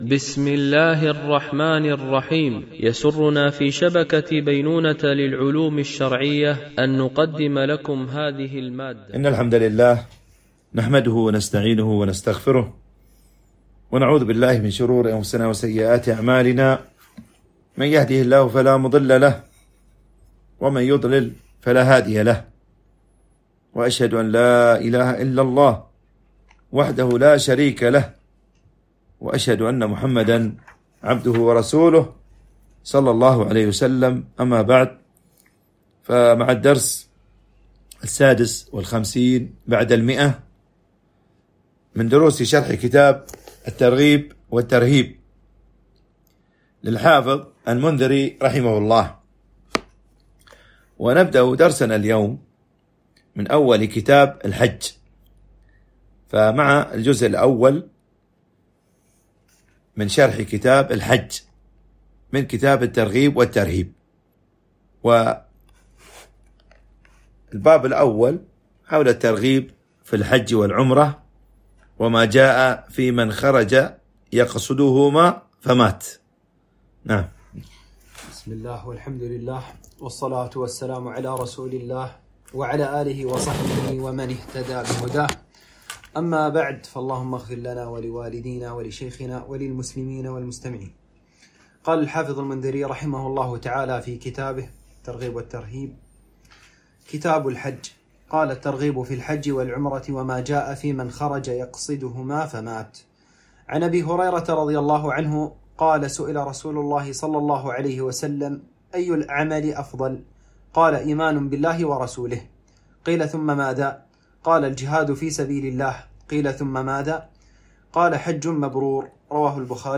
شرح كتاب الترغيب والترهيب - الدرس 156 ( كتاب الحج - الجزء الأول - باب الترغيب في الحج والعمرة )
التنسيق: MP3 Mono 44kHz 64Kbps (VBR)